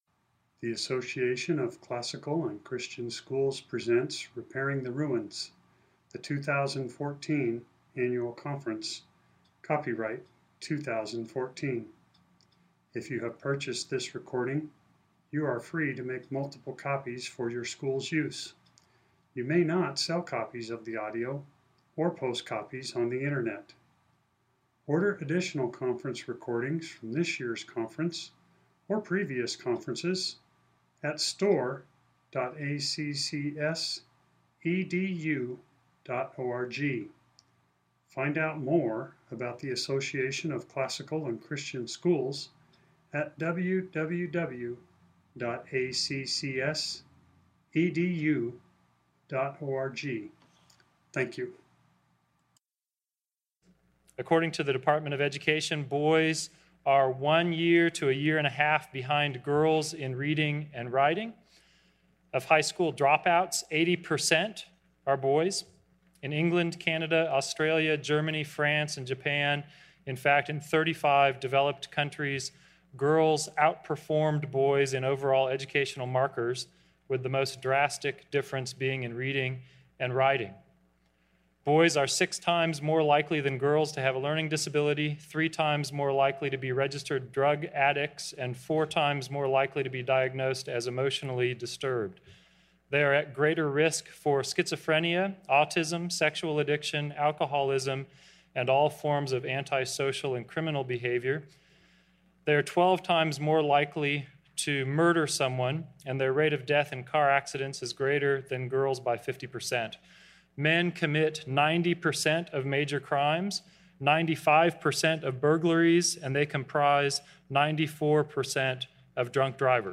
2014 Workshop Talk | 1:03:03 | K-6, Virtue, Character, Discipline
Additional Materials The Association of Classical & Christian Schools presents Repairing the Ruins, the ACCS annual conference, copyright ACCS.